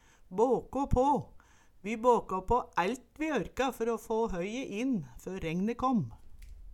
båkå på - Numedalsmål (en-US)